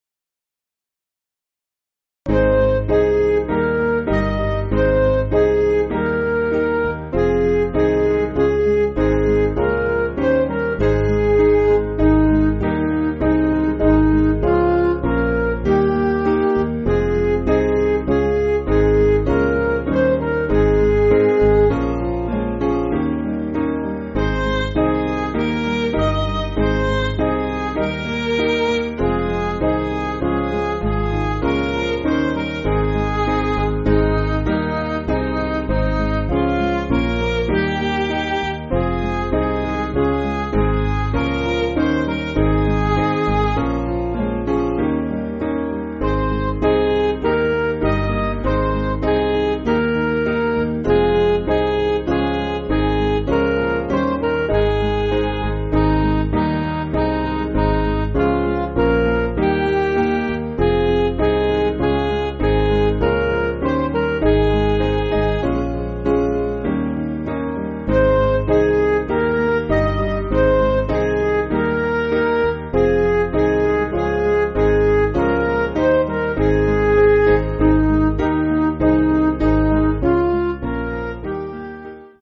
Piano & Instrumental
(CM)   5/Ab